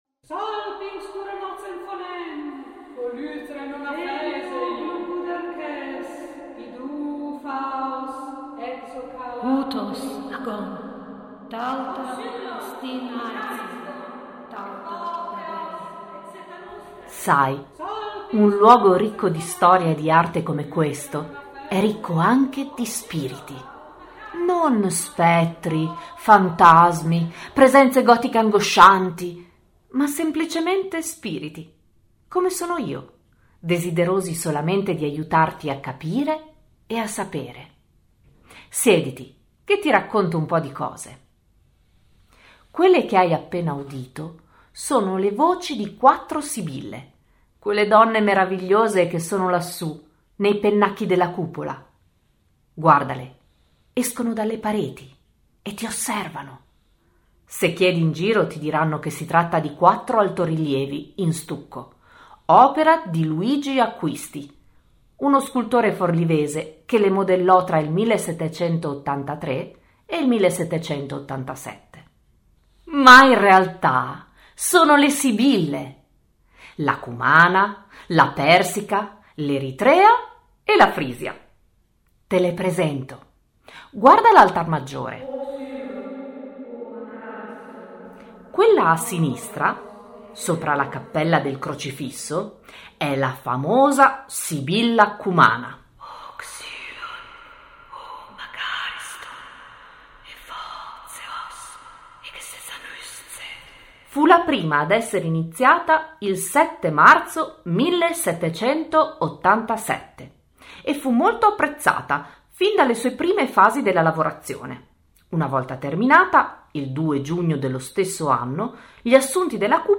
niccol� dell'arca compianto transito della vergine chiesa vita audio guide